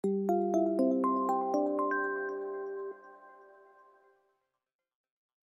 lg-poweron_24595.mp3